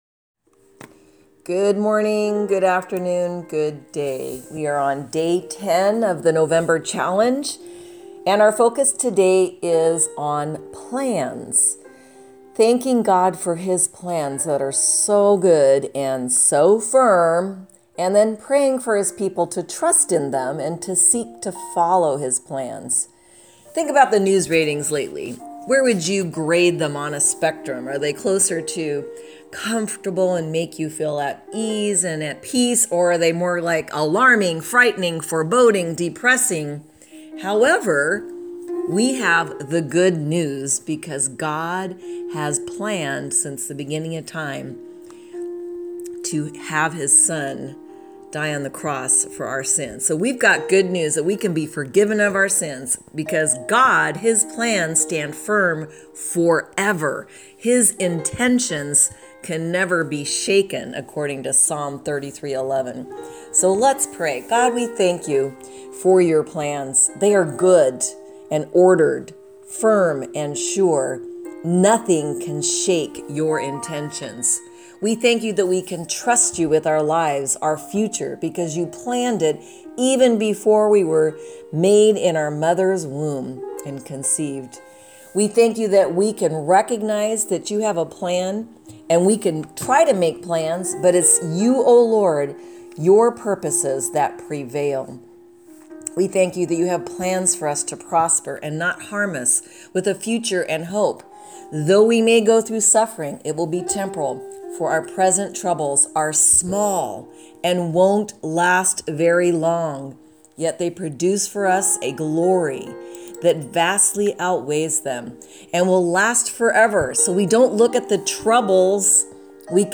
Listen to Today's Prayer Podcast